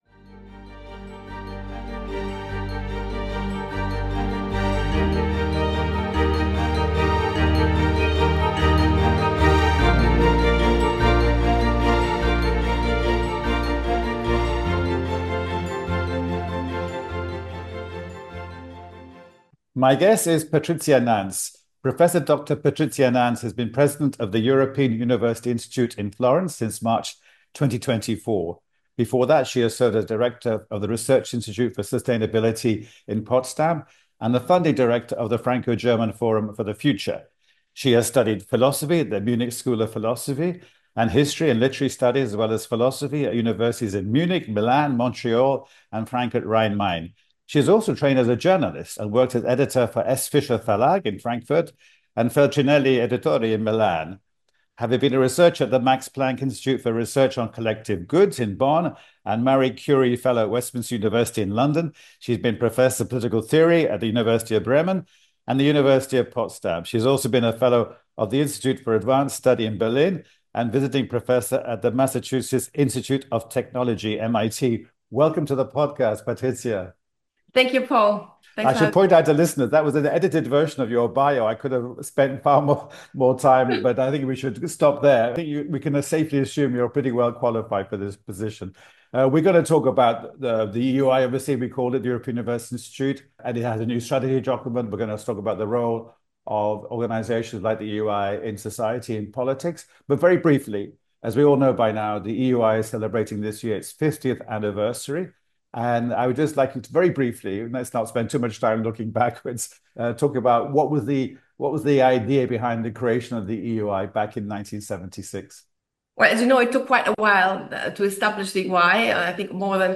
These podcasts are me in conversation with personalities that I think have interesting things to say. They are deliberately informal and are designed to be illuminating as well as, hopefully, entertaining.